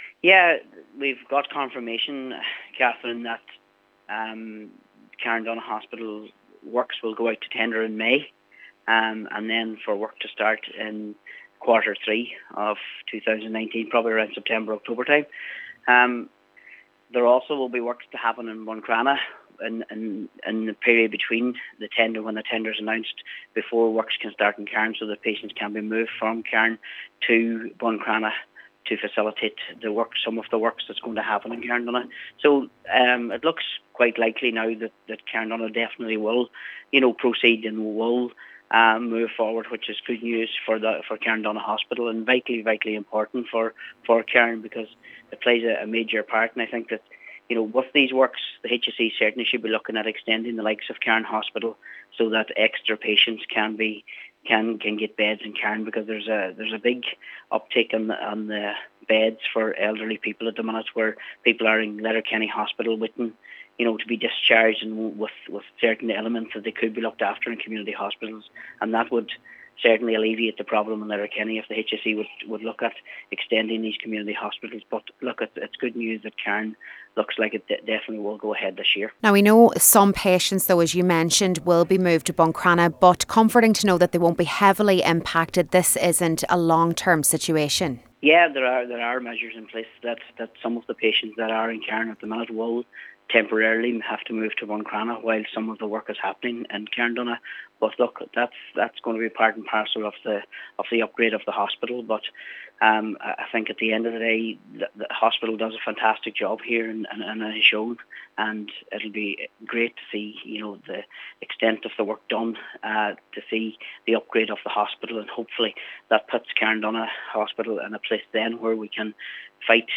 Cllr Martin McDermott says while this is major progress, an extension of the hospital would further cater to the community in the future: